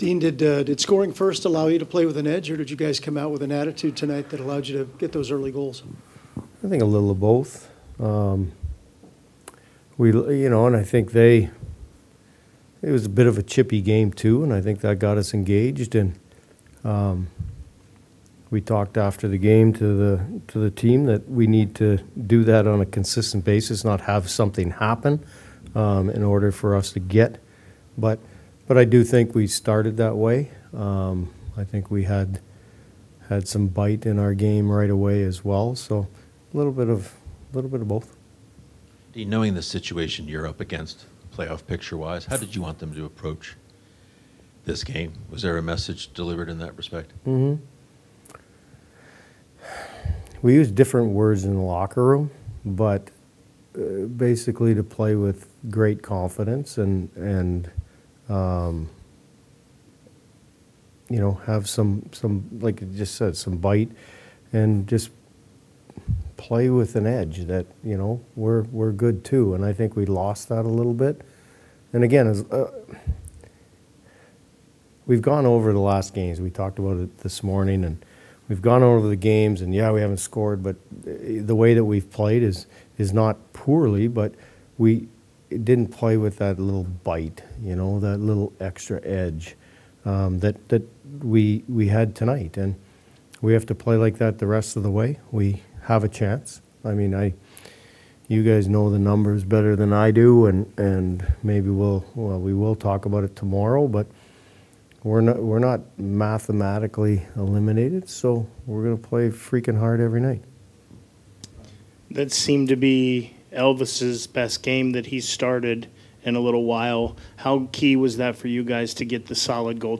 Dean Evason CBJ head coach Postgame Press conference after Jackets 5-2 win over Ottawa 4 8 2025